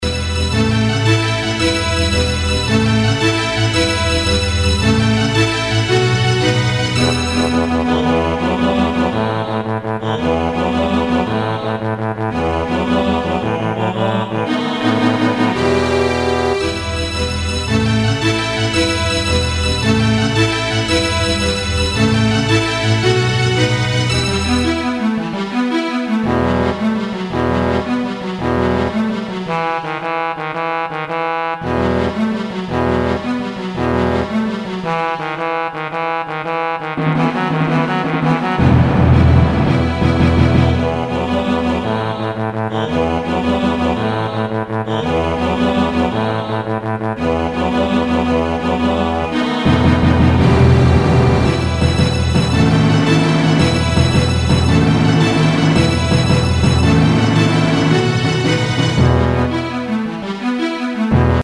Excerpt from Symphonic Song- "The Conversion of Nicodemus"/J. K. Davis
Computer Version- Digital Sound by Yamaha, Sibelius, HP and SoundBlaster